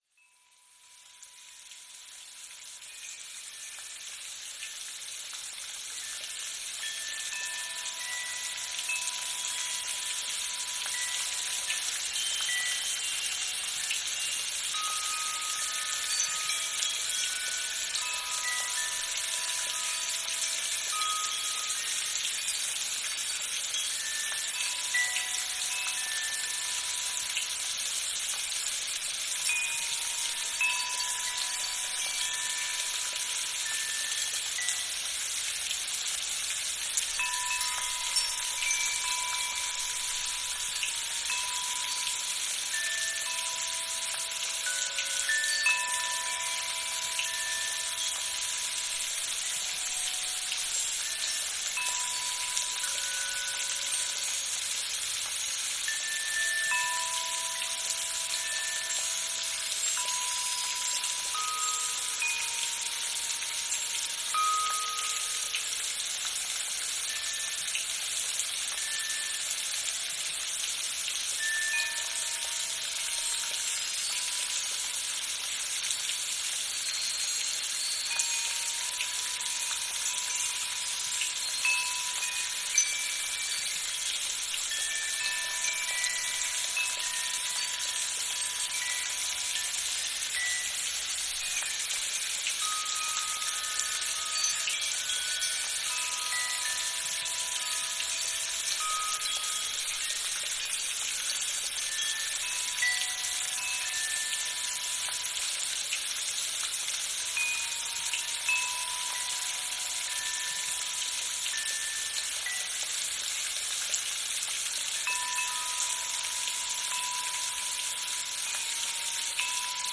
rainchimes.mp3